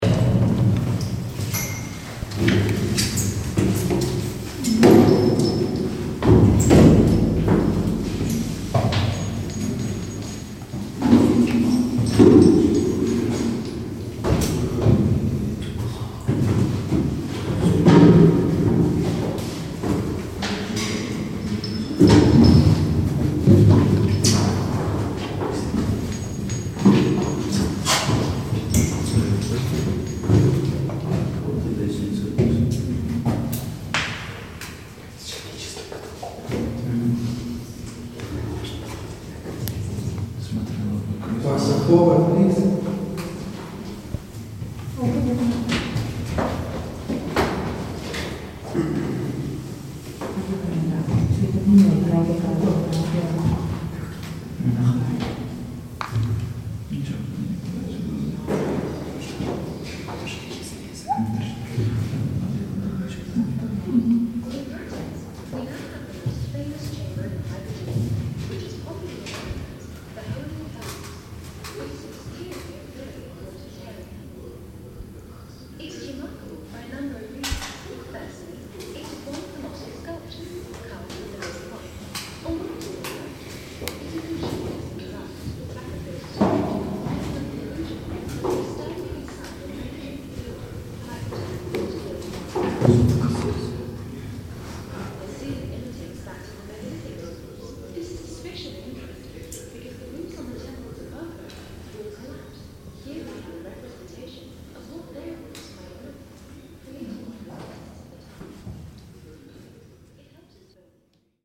On a tour in the Ħal Saflieni Hypogeum, walking around. You can here other tourists on the tour and recorded guides.